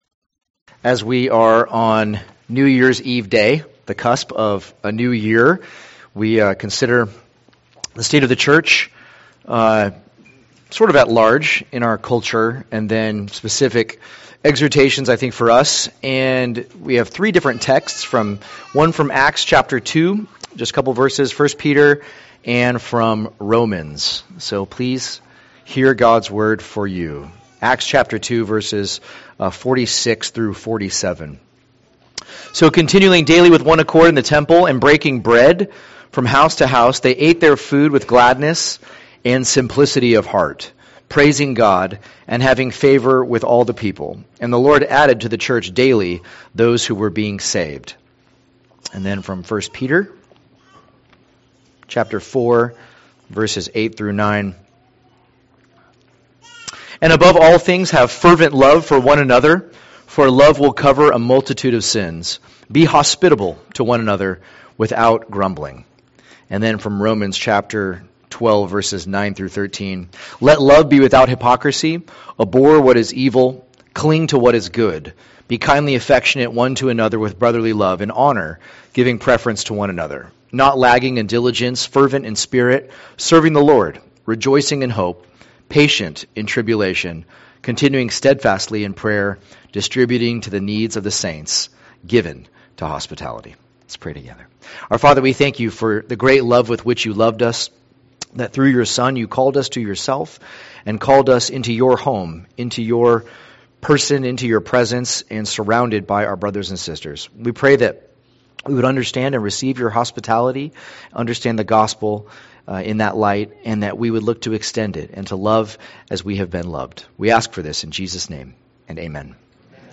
2023 State of the Church Preacher